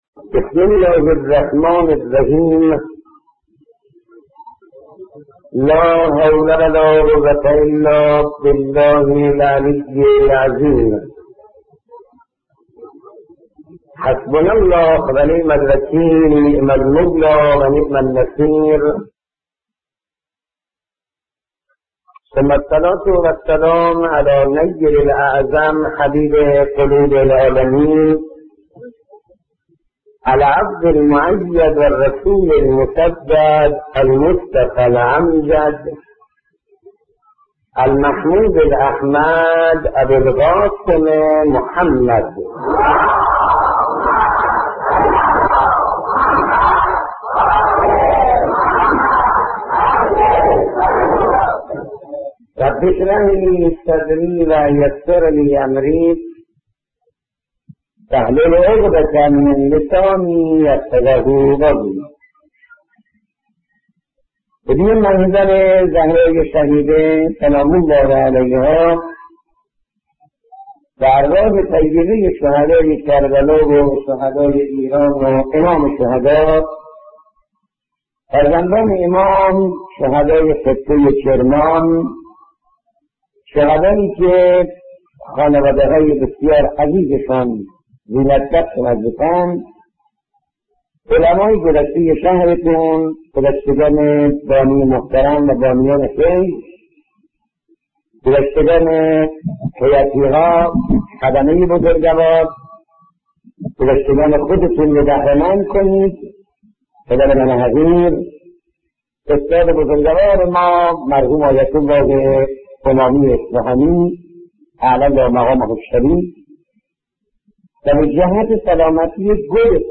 دانلود مجموعه سخنرانی های حجت الاسلام مهدی دانشمند